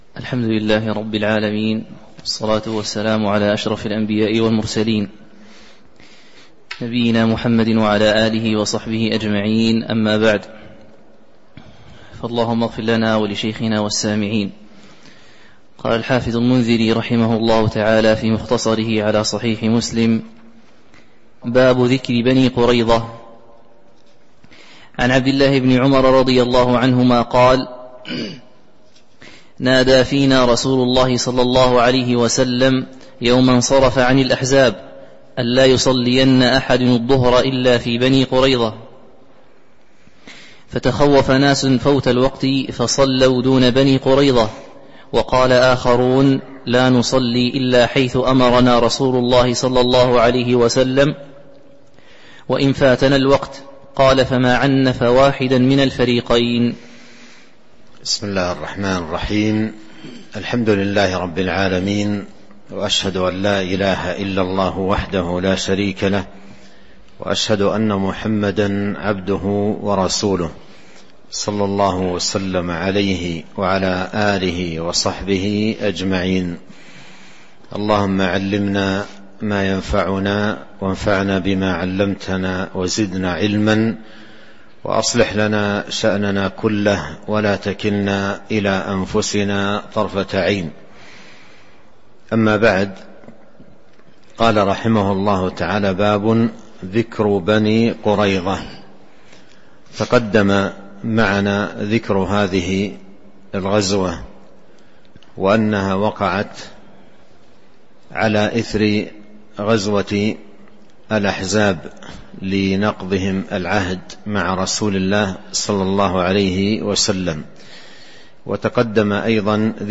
تاريخ النشر ٨ جمادى الأولى ١٤٤٣ هـ المكان: المسجد النبوي الشيخ: فضيلة الشيخ عبد الرزاق بن عبد المحسن البدر فضيلة الشيخ عبد الرزاق بن عبد المحسن البدر باب ذكر بني قريظة (06)